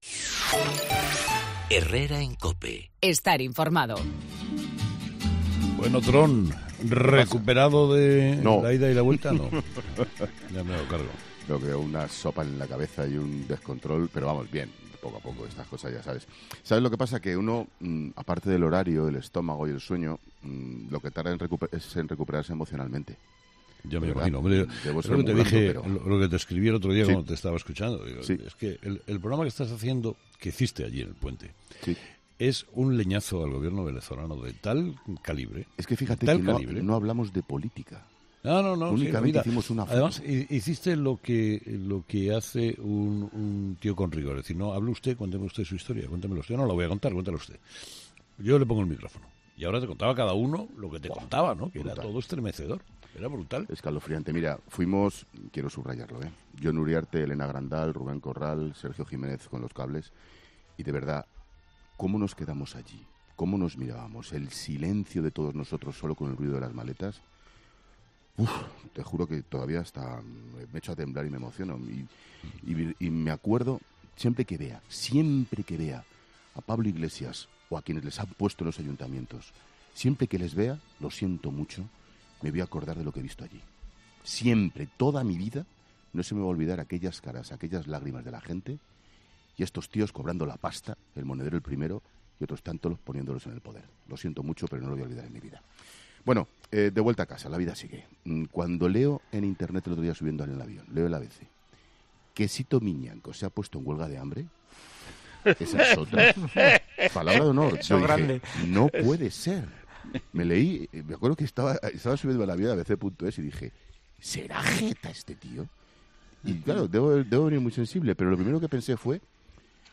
AUDIO: El comentario de Ángel Expósito en 'Herrera en COPE' de este miercoles 28 de febrero de 2018